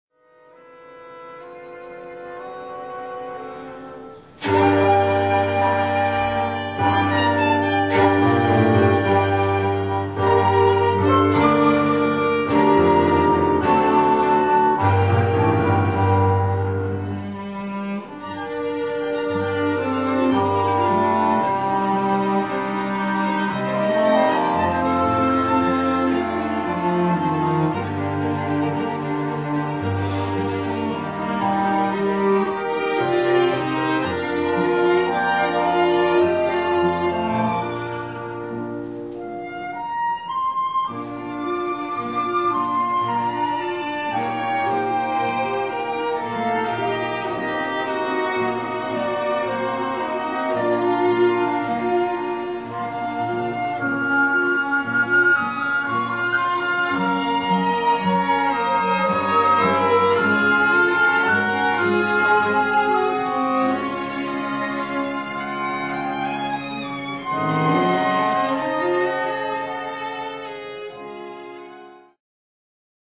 音楽ファイルは WMA 32 Kbps モノラルです。
Flute、English Horn、Clarinet、Violin、Cello × 2、Piano